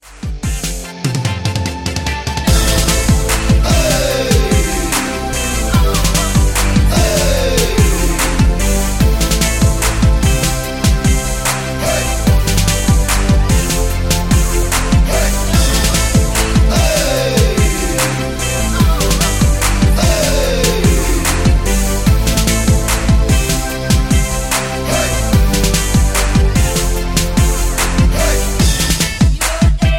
Backing track files: Duets (309)
Buy With Backing Vocals.